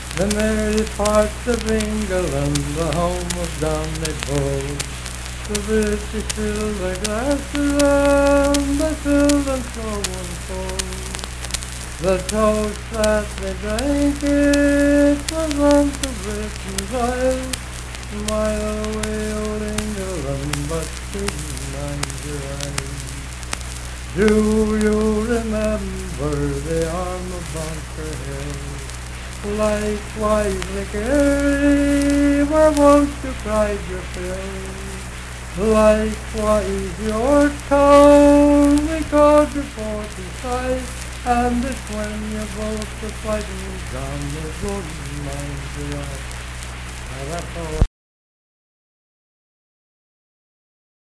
Unaccompanied vocal music
Voice (sung)
Nicholas County (W. Va.), Richwood (W. Va.)